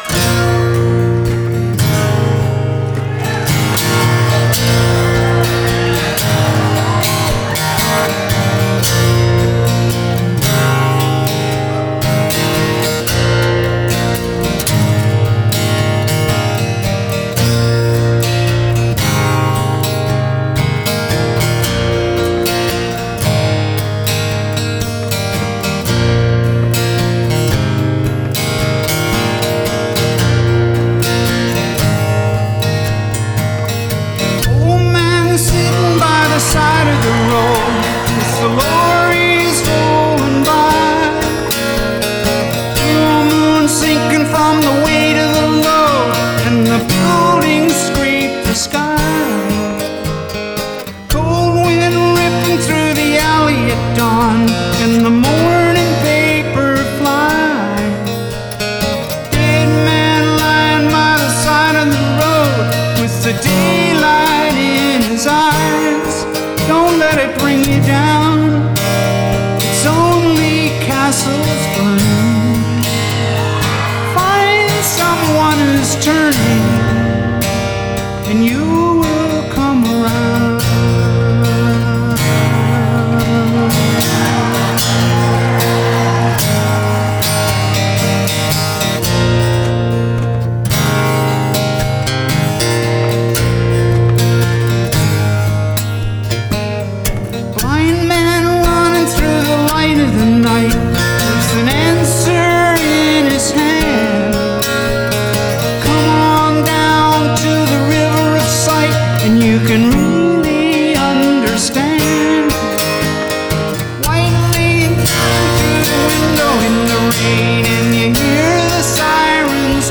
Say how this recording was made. First song, last show at The Garden, by the way.